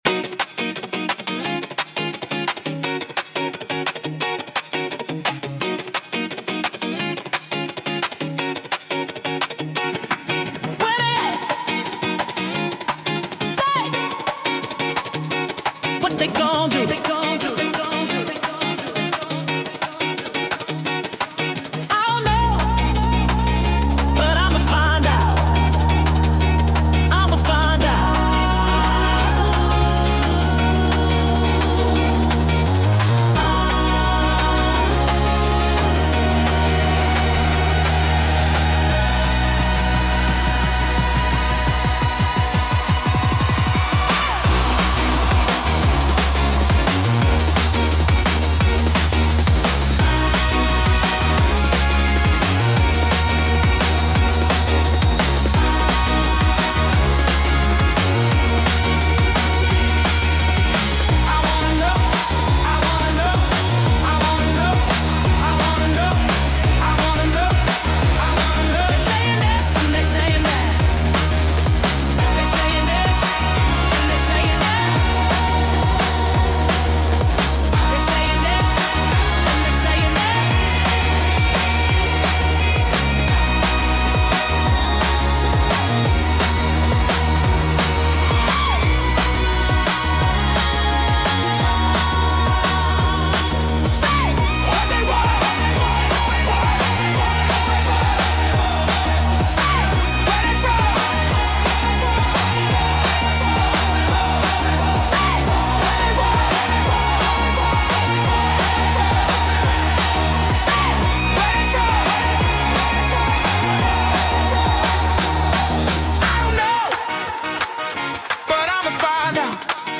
A fresh summer DNB anthem
a smooth, energetic drum & bass track
🎶 With crisp beats, warm basslines